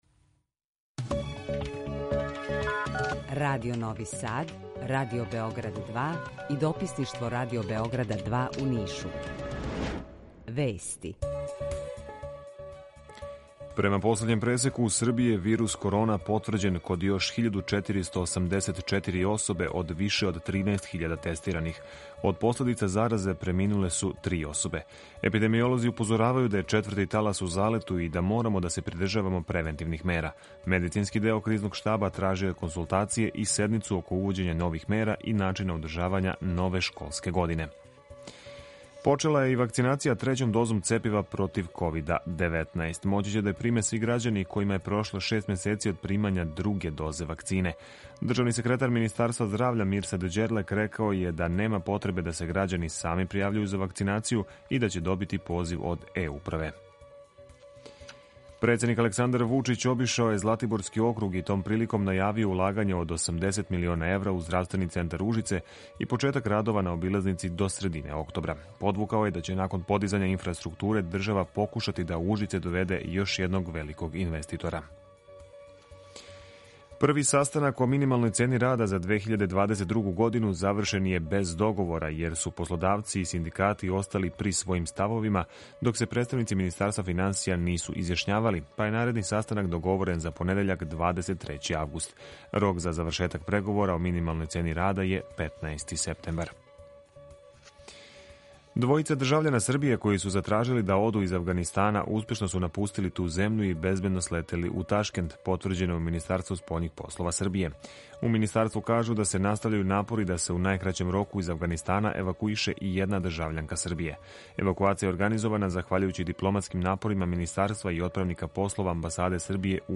Укључење Косовске Митровице
У два сата, ту је и добра музика, другачија у односу на остале радио-станице.